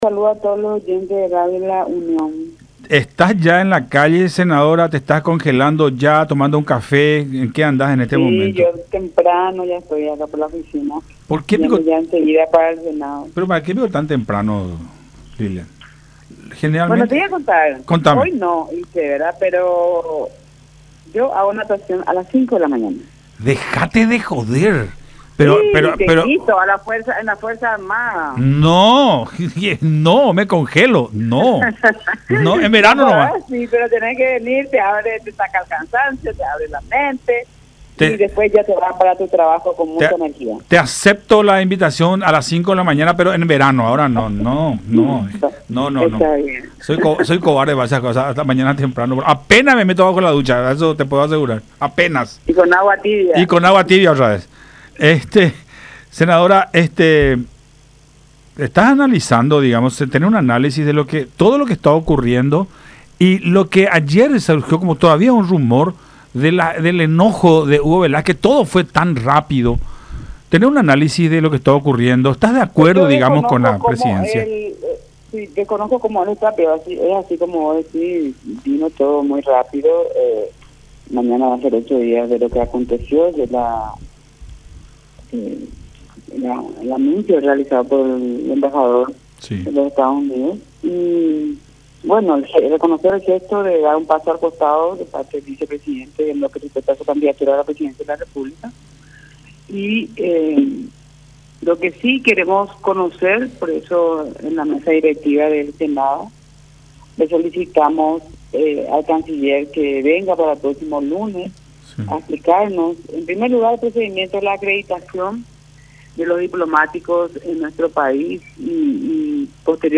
Se trata de un caso inédito, ya que es un vicepresidente en funciones”, expuso la parlamentaria en contacto con Nuestra Mañana por Unión TV y radio La Unión.